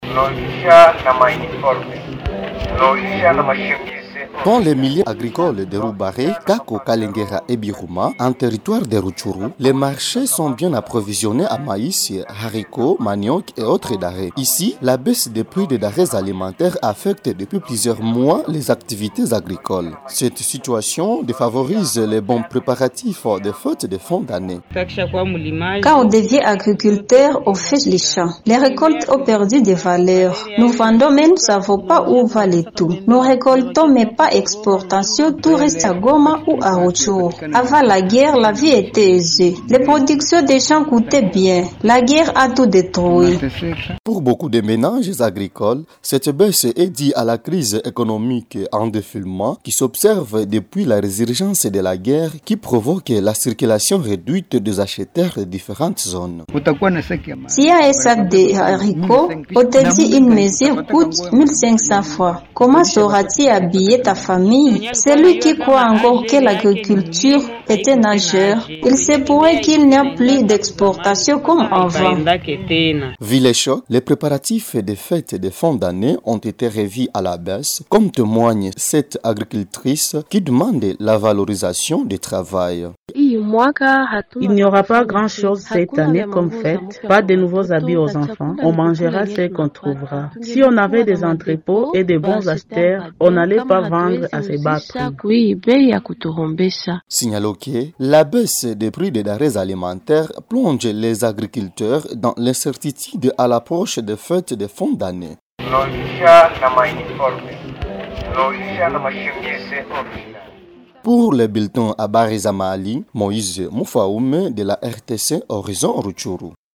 Vu ce choc, les préparatifs de fêtes de fin d’année ont été revus à la baisse, comme témoigne cette agricultrice, qui demande la valorisation de travail.